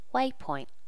Updated default sound set for notify plugin
waypoint.wav